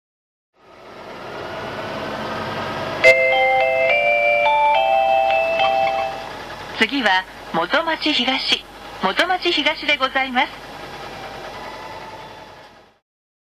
車内アナウンスコレクション
国際興業グループの十和田観光バスです。国際興業バスで以前使われていたチャイムが流れます！